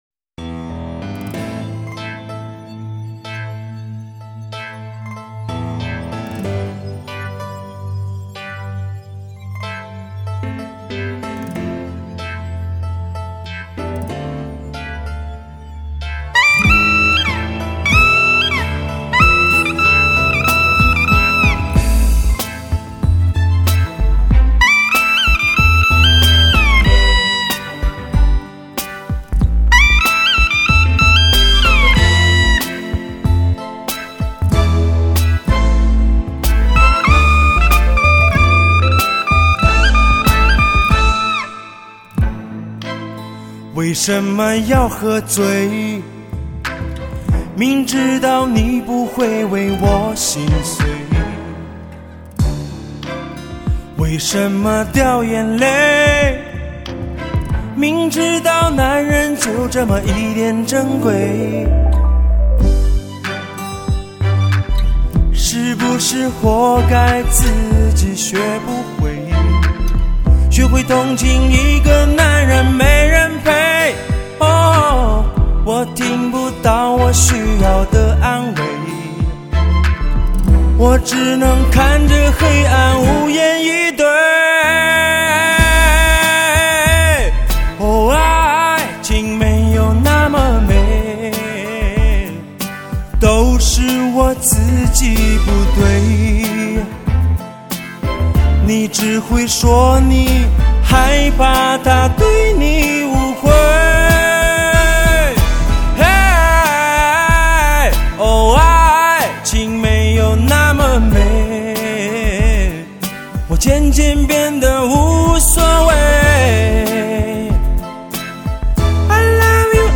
极品高保真唱片
清澈而富有磁性的声音，
再加上娓婉的演唱技巧，
真诚的情感带着一丝淡淡的忧伤，